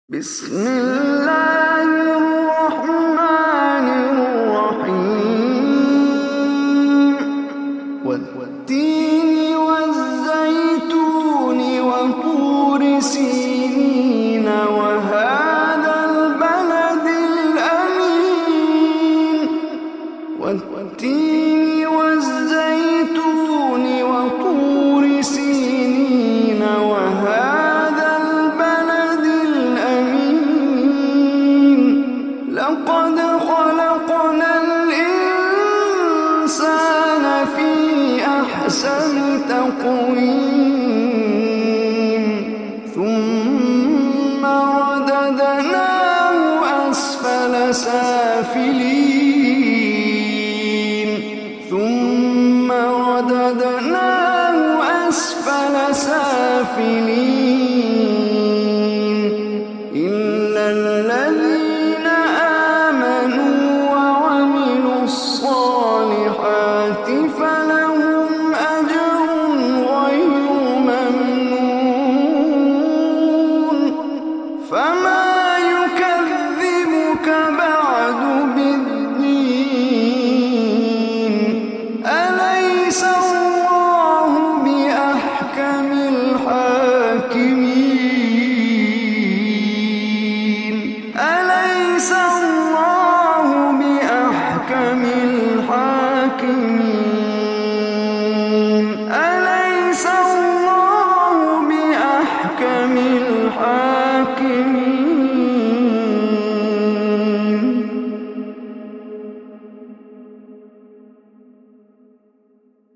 Surah Tin MP3 Recitation by Omar Hisham Arabi
095-surah-at-tin.mp3